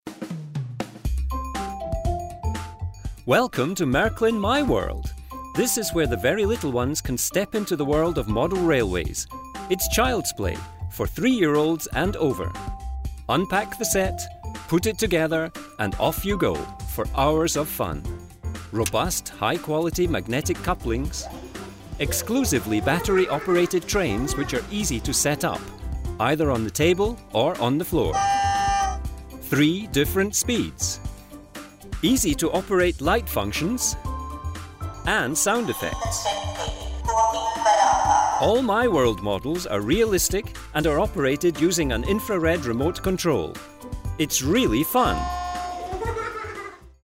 Englische Vertonung: